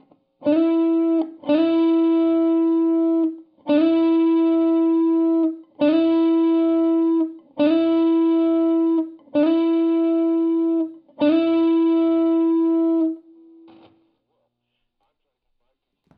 Sounds great if plucking the string lightly, but, with notes with a hard attack I am hearing a non-harmonic distortion of some kind...very low pitch.
It is a low note, that pulses twice, with each attack of the main note.
Nope, not blocking distortion - that's 120 Hz, most likely ripple from the power supply.
Listening to the sample, almost sounds like it could be fret buzz farther up the neck.
amp_distortion_artifact.WAV